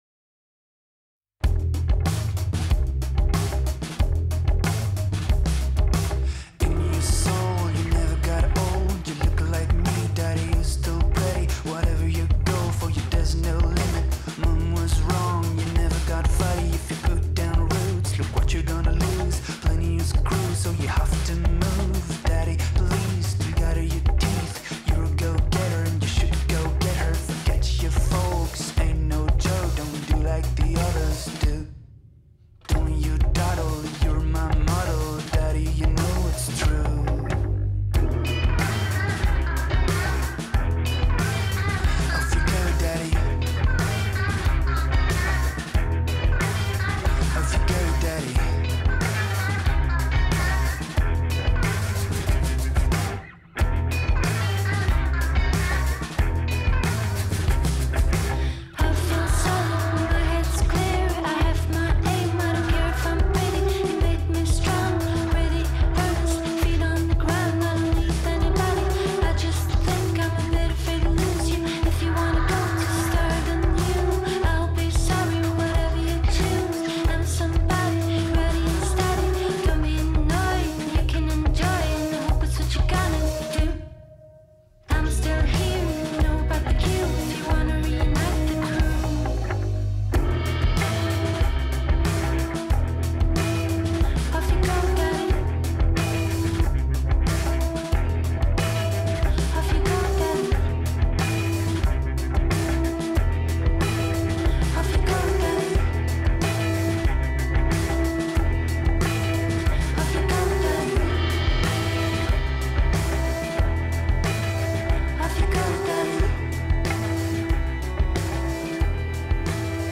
Interview BRNS